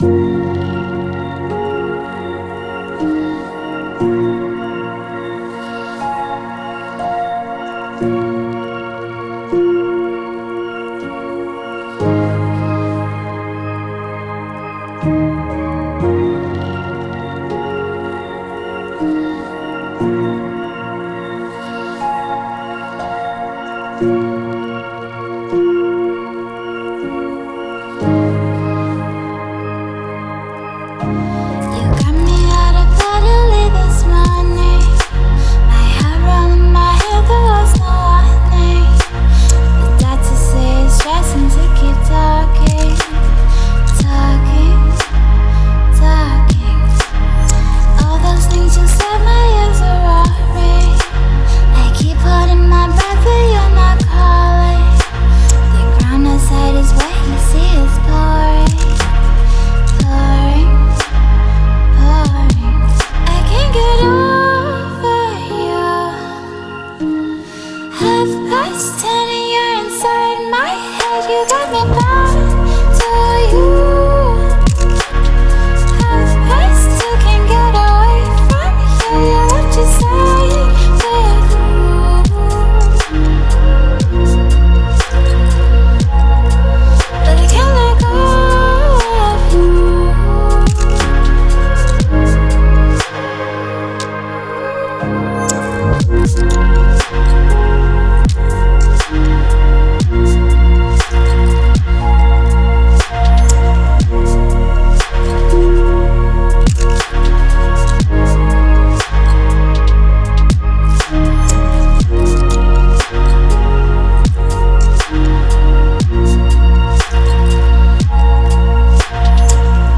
Ambient, Chillstep, Electronic, Future Garage
Vocals